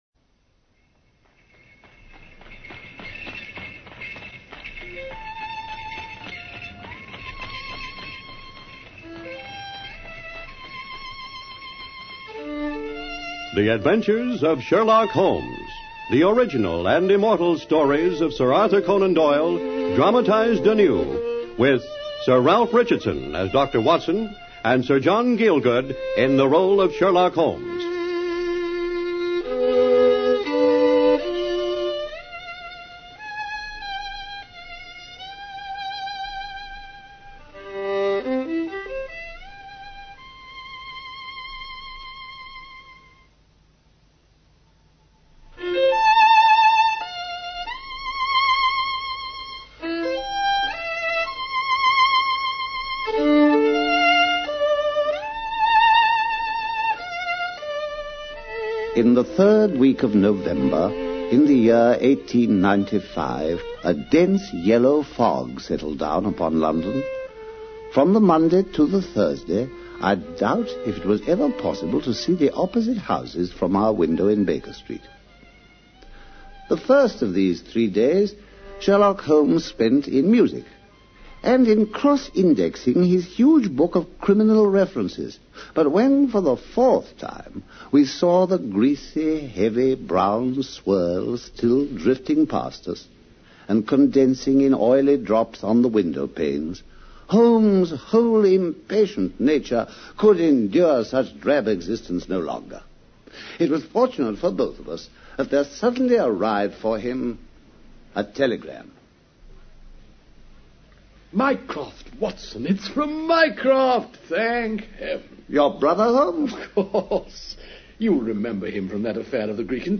Radio Show Drama with Sherlock Holmes - The Bruce Partington Plans 1954